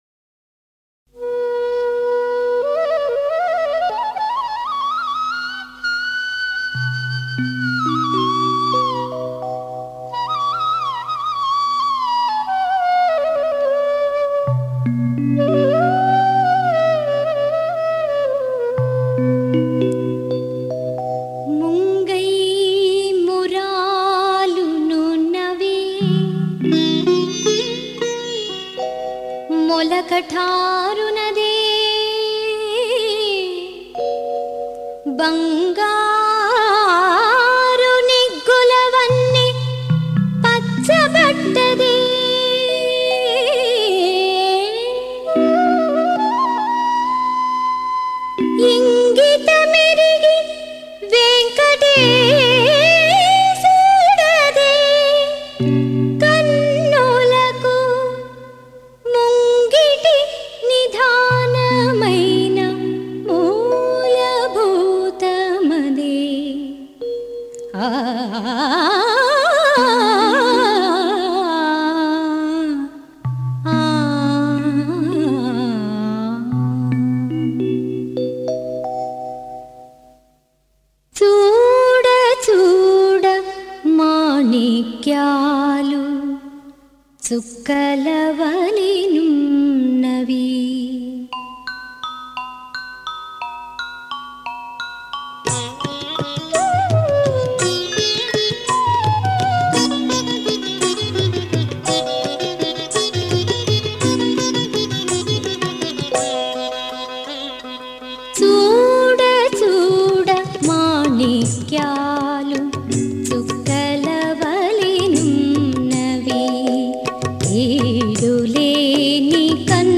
సంకీర్తన